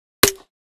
click_layer.ogg